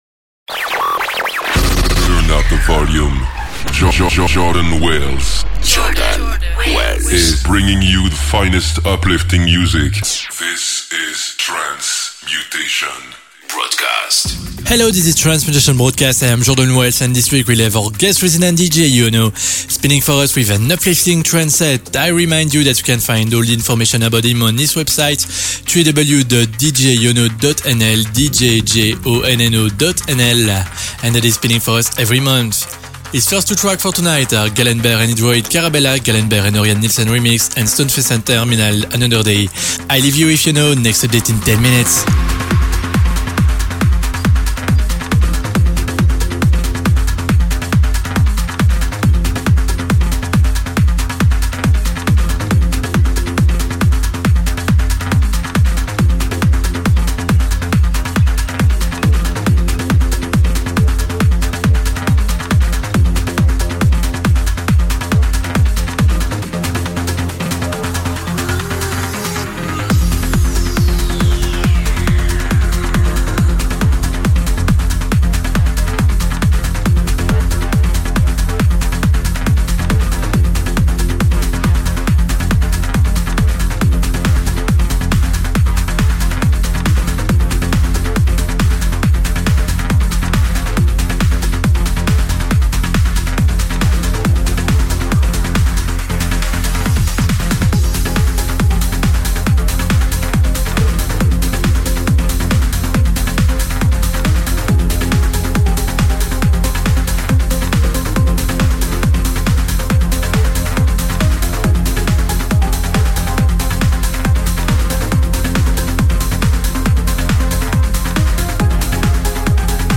uplifting trance set